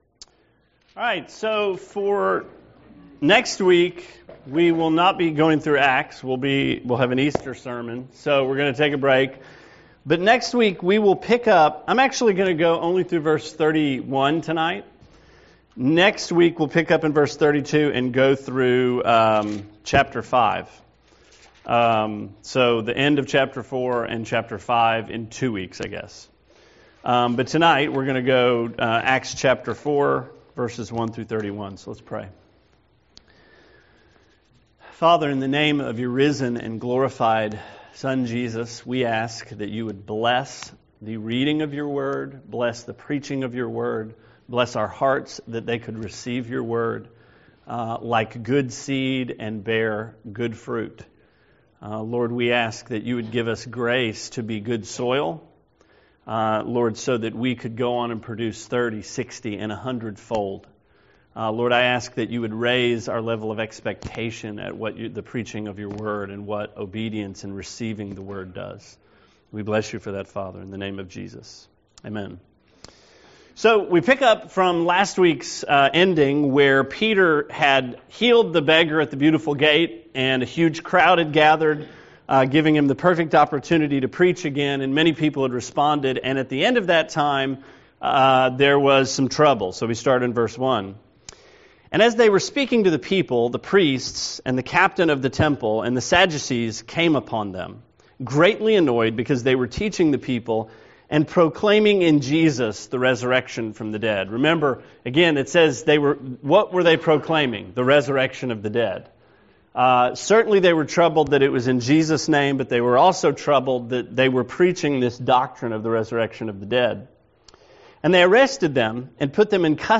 Sermon 3/18: Acts 4